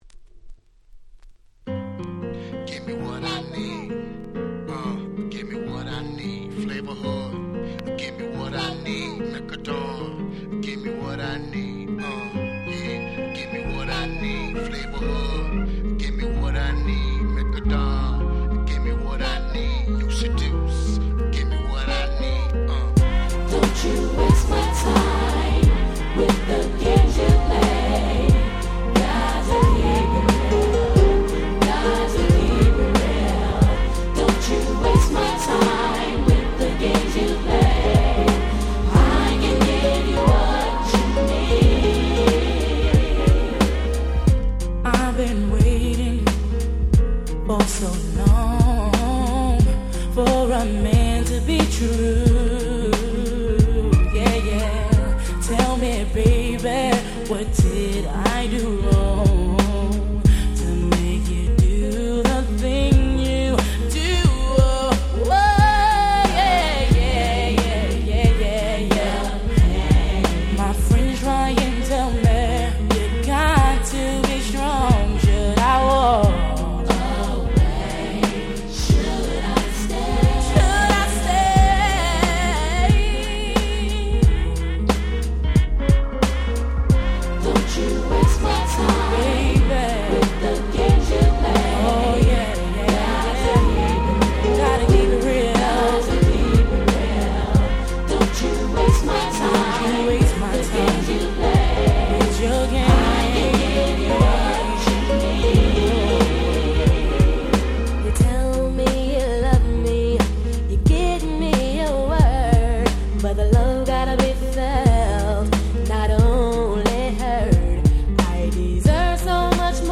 96' Very Nice Slow Jam / R&B / Hip Hop Soul !!